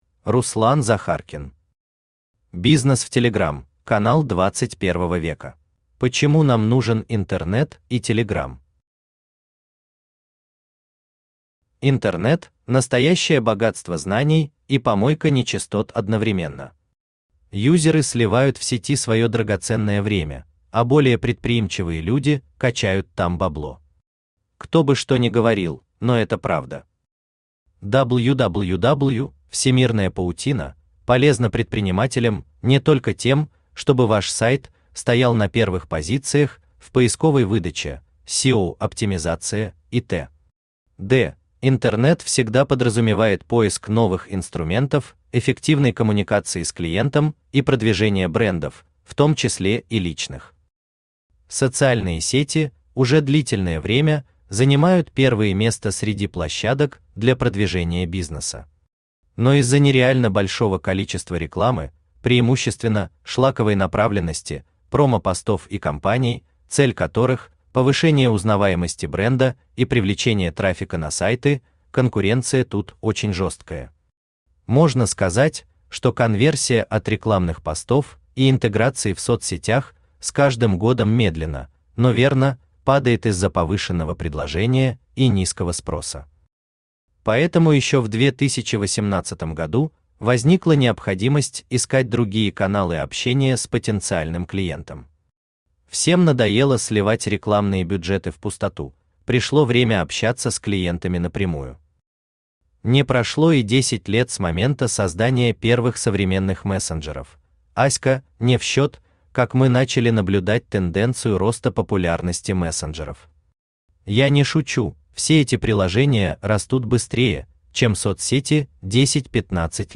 Aудиокнига Бизнес в Telegram: канал XXI века Автор Руслан Игоревич Захаркин Читает аудиокнигу Авточтец ЛитРес.